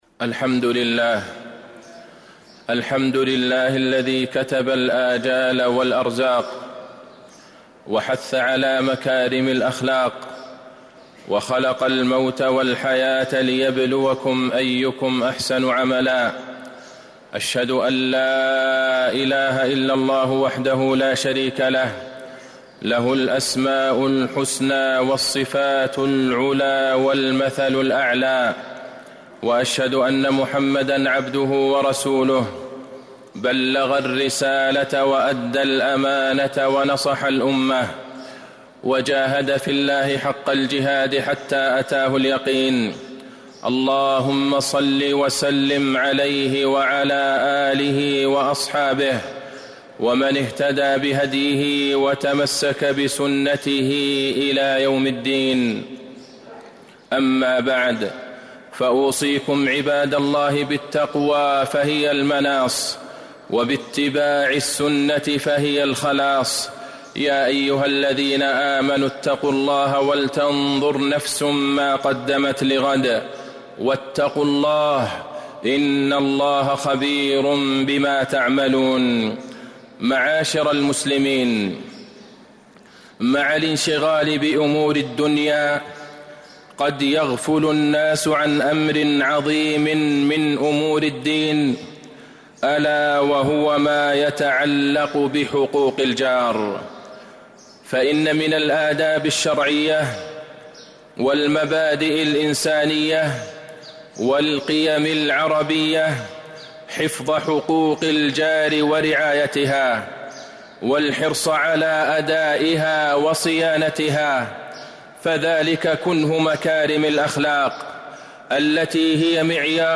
المدينة: من حقوق الجار - عبد الله بن عبد الرحمن البعيجان (صوت - جودة عالية